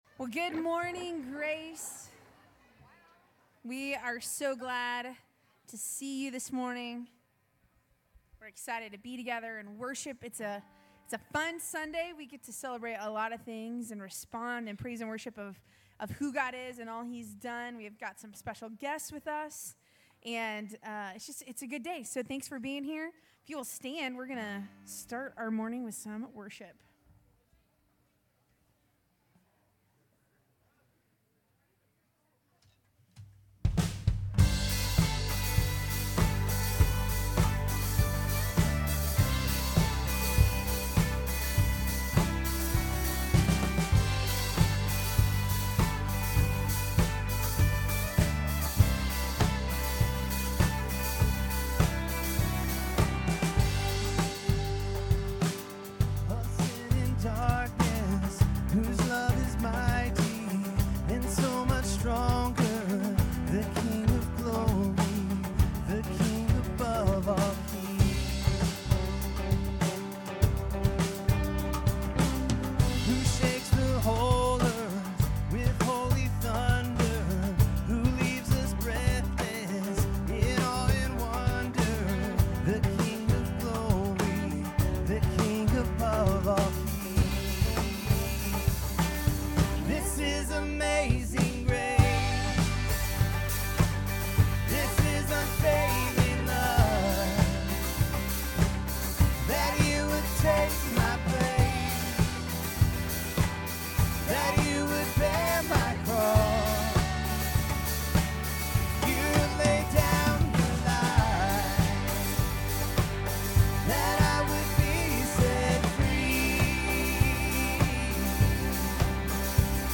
Grace Community Church Lindale Campus Sermons Mayflower Church Aug 13 2023 | 00:47:02 1x 00:00 / 00:47:02 Subscribe Share RSS Feed Share Link Embed